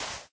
sand2.ogg